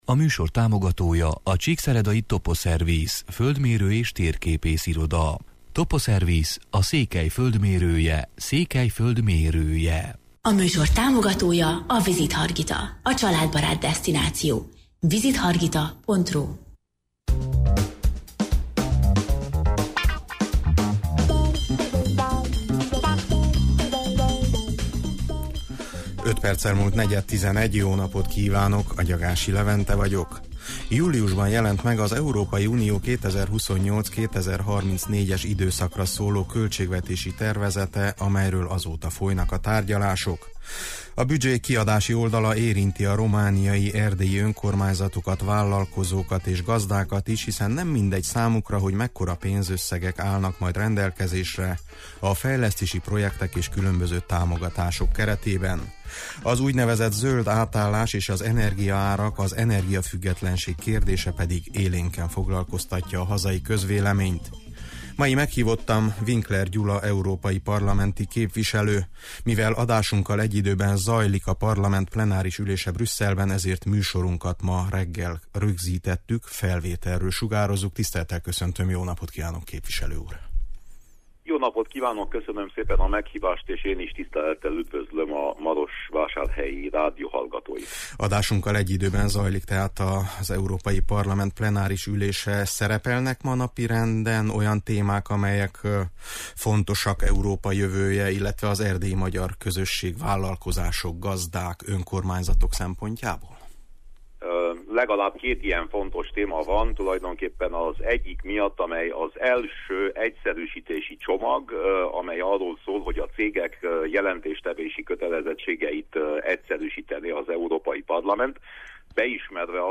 Meghívottam Winkler Gyula Európai Parlamenti képviselő: